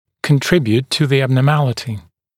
[kən’trɪbjuːt tə ən ˌæbnɔː’mælɪtɪ][кэн’трибйут ту эн ˌэбно:’мэлити]служить причиной деформации, способствовать возникновению деформации